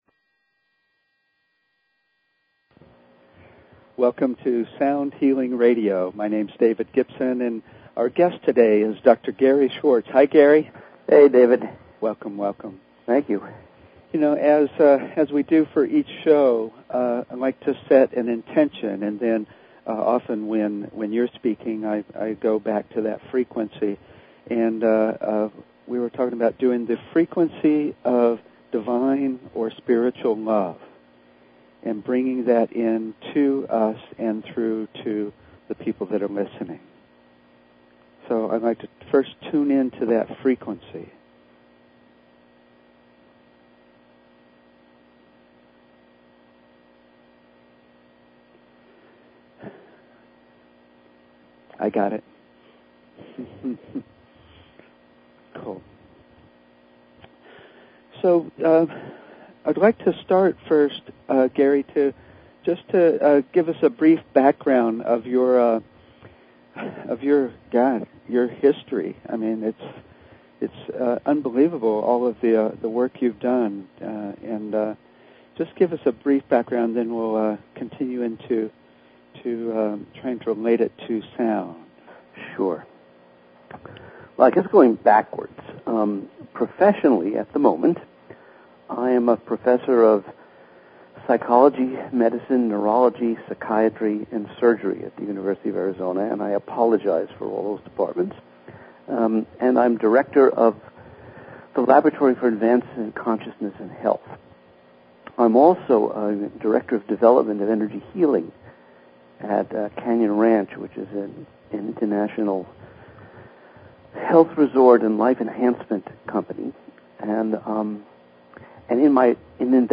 Talk Show Episode, Audio Podcast, Sound_Healing and Courtesy of BBS Radio on , show guests , about , categorized as
Sound Healing Talk Show